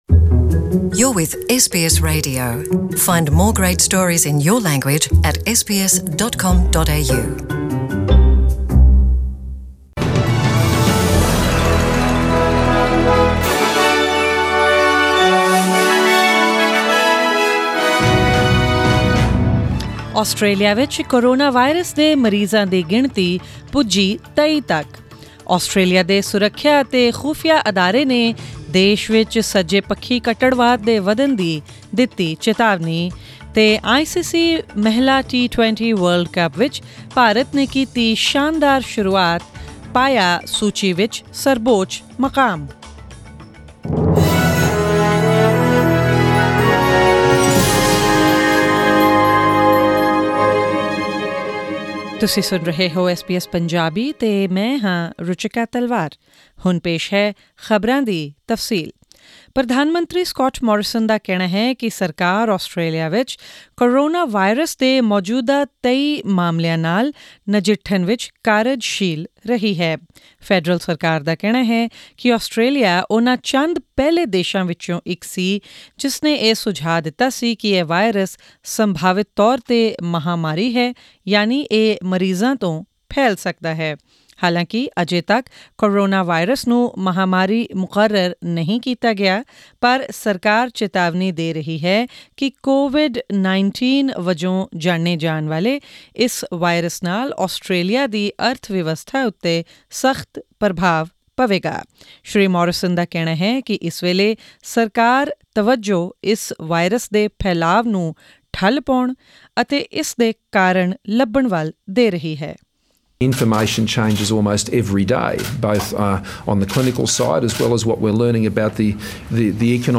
Australian News in Punjabi: 25 February 2020